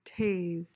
PRONUNCIATION: (tayz) MEANING: verb tr.: To incapacitate or subdue by delivering an electric shock using a stun gun.